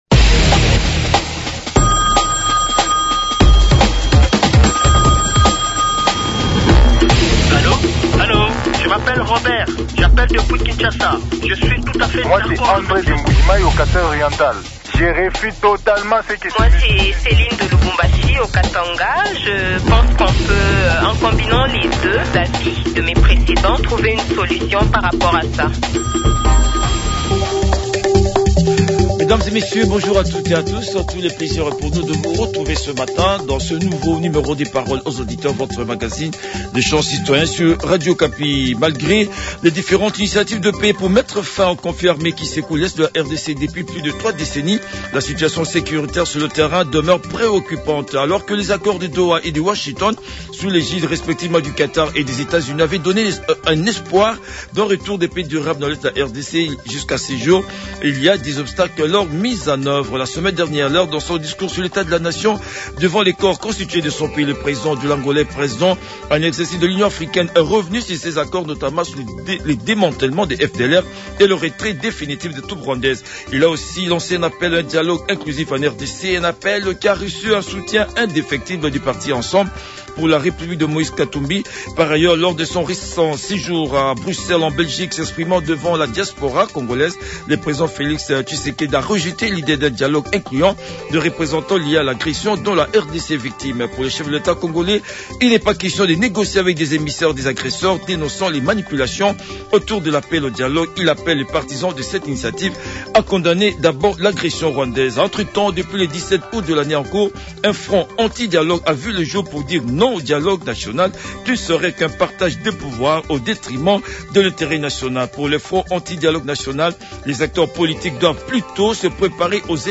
Les auditeurs ont débattu